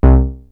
SYNTH BASS-2 0002.wav